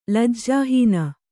♪ lajjāhīna